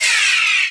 laser-beam-02.ogg